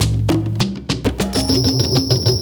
Percussion 02.wav